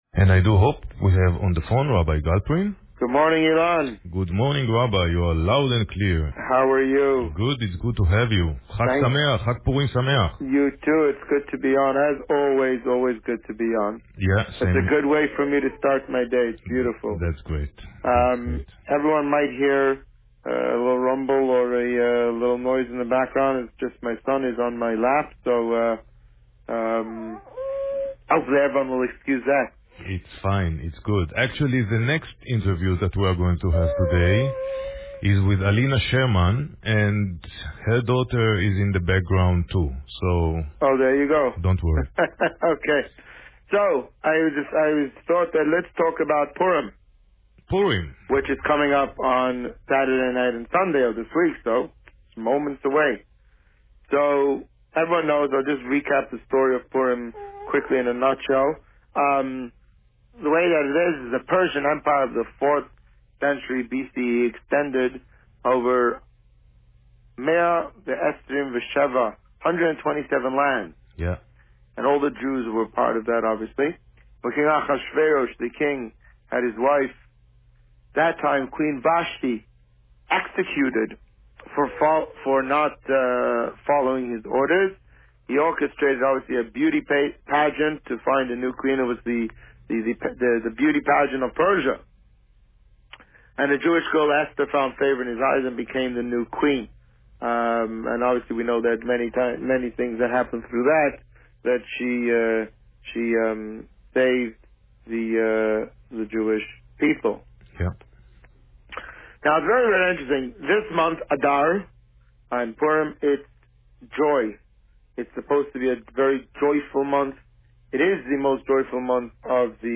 This week, the Rabbi spoke about the meaning of Purim and the upcoming Purim party. Listen to the interview here.